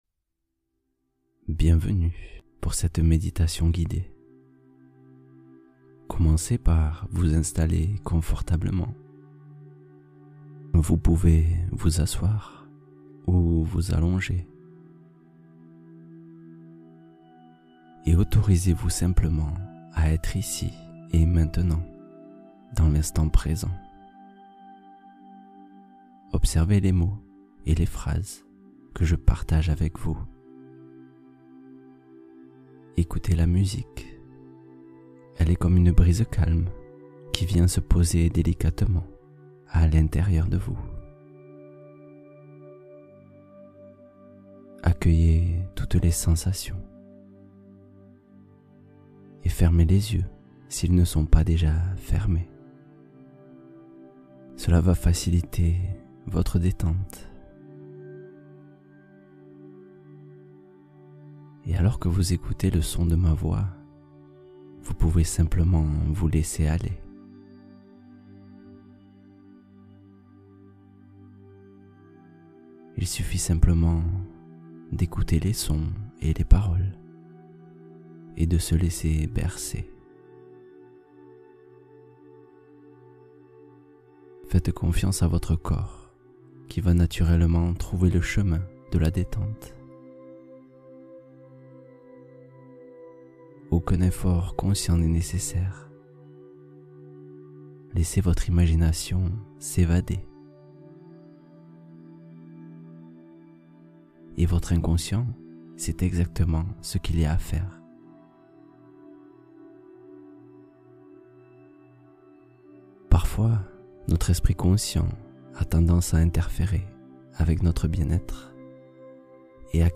Confiance et lâcher prise : méditation guidée avec affirmations positives